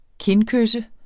Udtale [ ˈken- ]